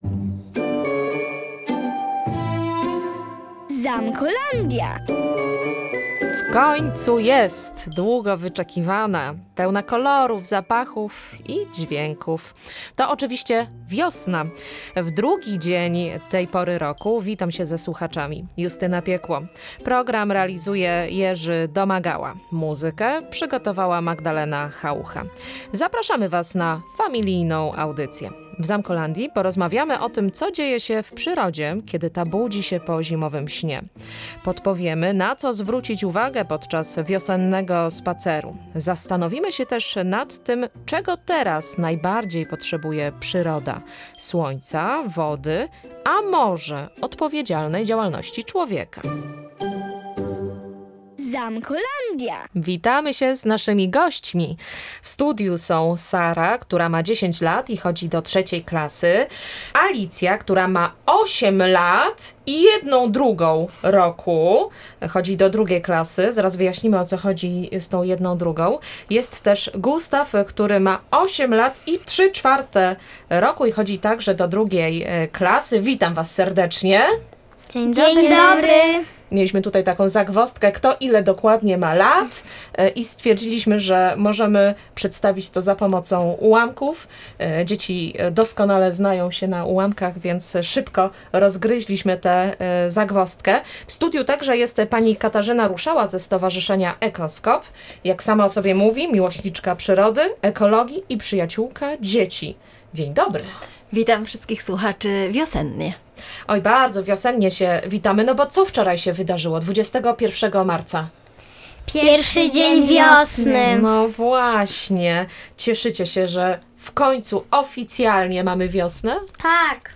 O tym, jak ją obserwować i czego teraz najbardziej potrzebuje, rozmawiali uczestnicy audycji — dzieci i ekspertka ze Stowarzyszenia Ekoskop.